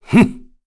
Kain-Vox_Attack1_kr.wav